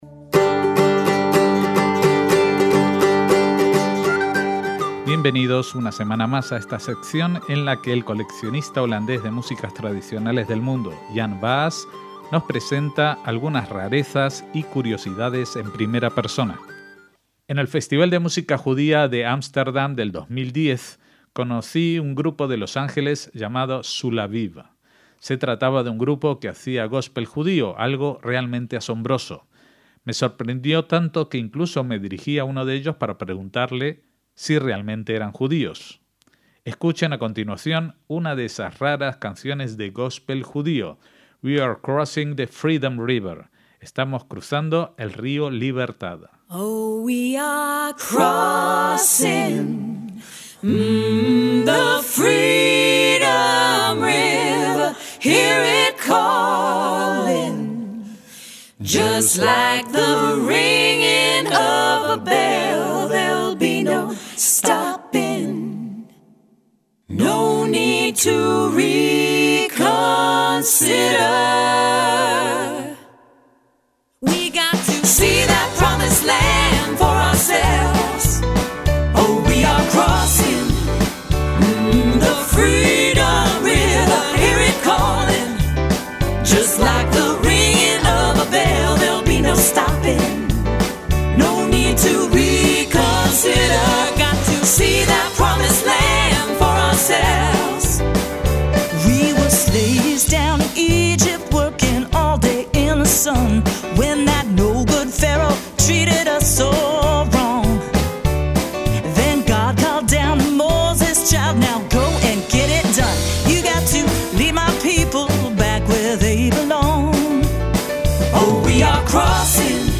como esta selección de sorprendentes temas sonoros, desde soul judío y klezmer no judío, a la música en ídish... de Hawai.